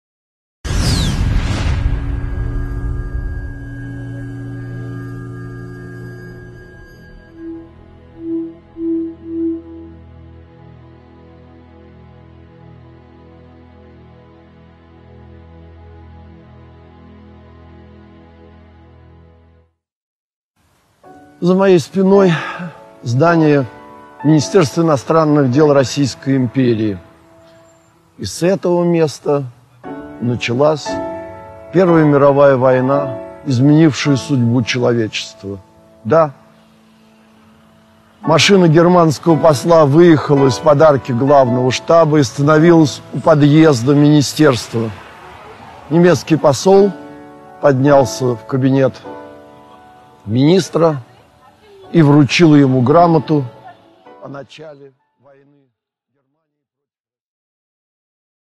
Аудиокнига Санкт-Петербург времен Первой мировой войны.